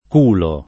culo [ k 2 lo ]